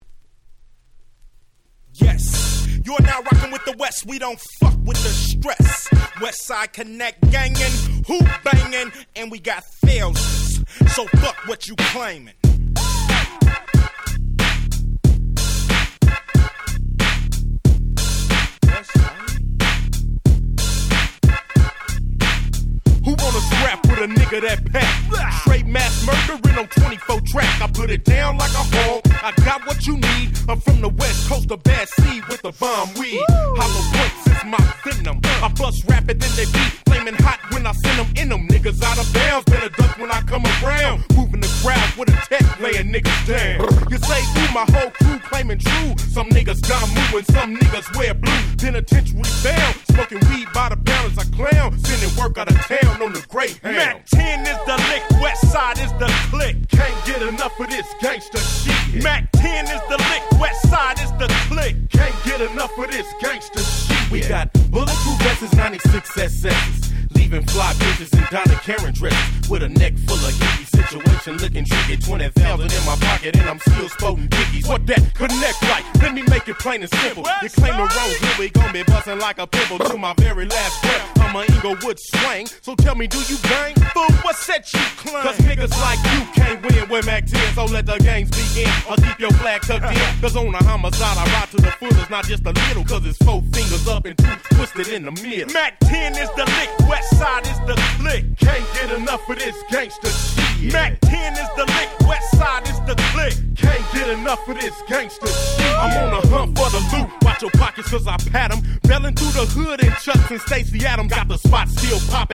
96' Smash Hit West Coast Hip Hop !!
めっちゃバンギン！！